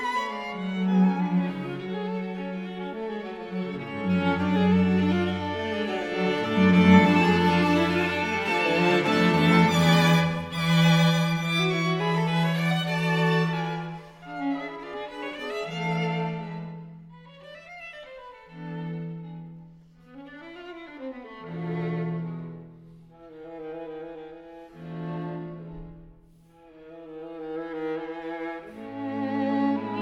Le Quatuor Heath consacre son premier enregistrement sous le label Harmonia Mundi au contraste saisissant des quatuors de Tchaïkovski : le Premier (avec le célèbre et plaintif Andante cantabile) et le fougueux Troisième (à la mémoire de Ferdinand Laub).
String quartet Nʻ1, op. 11, ré majeur
"enPreferredTerm" => "Musique de chambre"